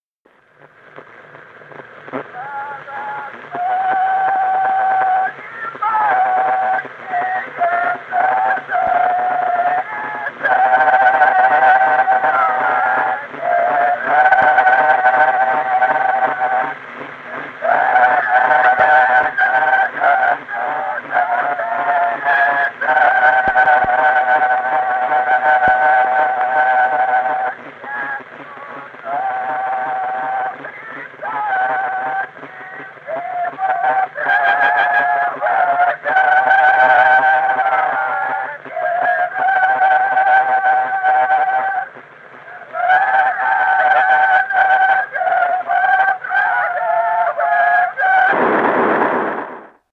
Народные песни Стародубского района «Благослови, мати», весняная девичья.
запев
подголосник
с. Остроглядово.